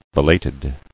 [be·lat·ed]